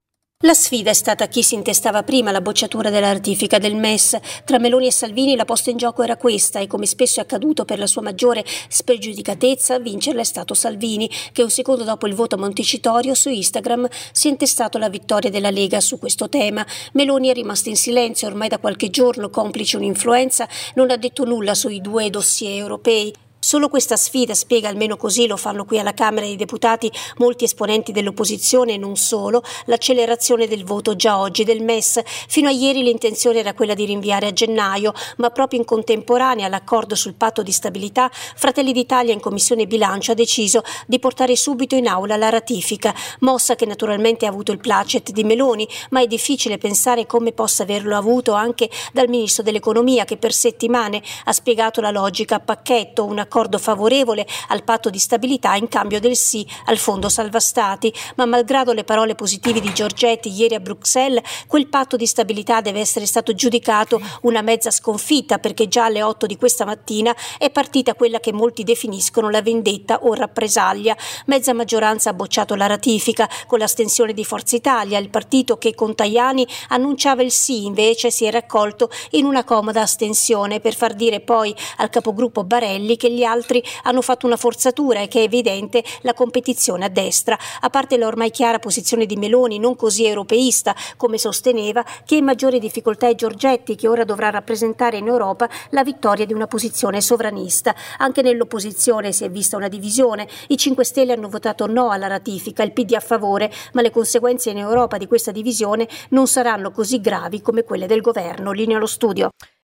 Da Roma